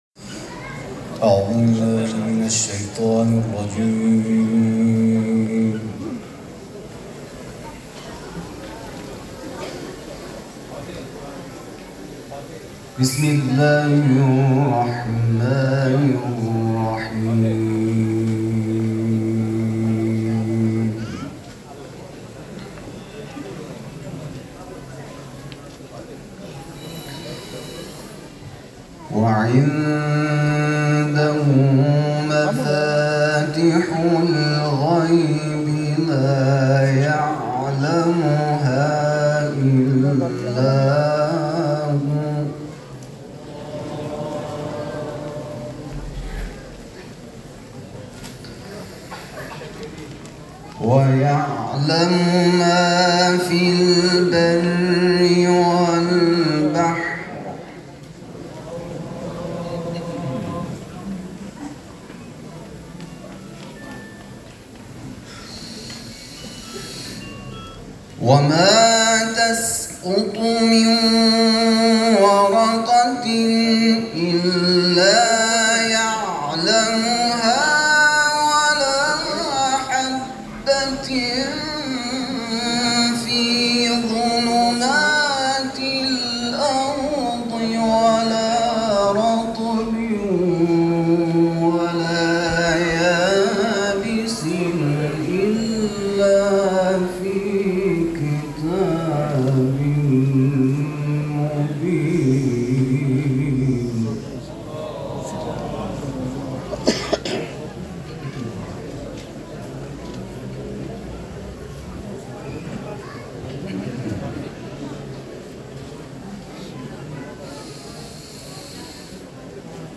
قاری شرکت‌کننده در چهل و پنجمین دوره مسابقات سراسری قرآن